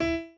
b_pianochord_v100l8o5e.ogg